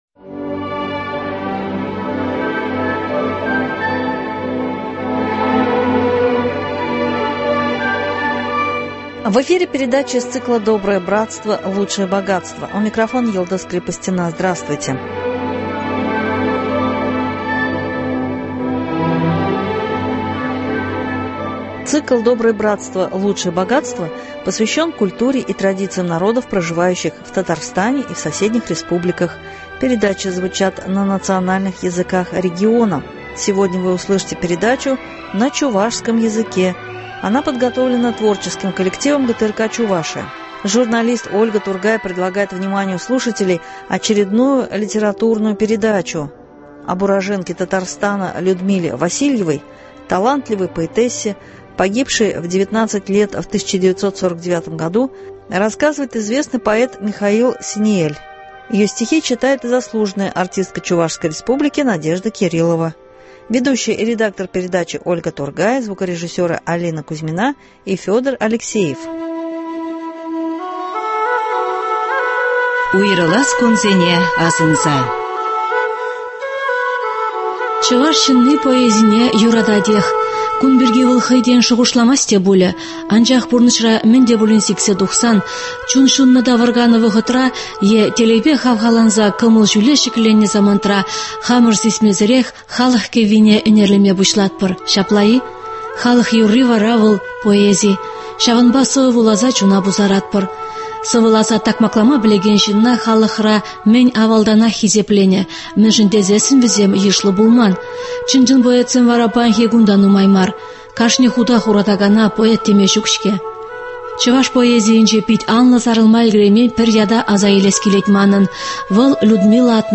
литературную передачу